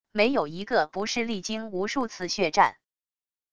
没有一个不是历经无数次血战wav音频生成系统WAV Audio Player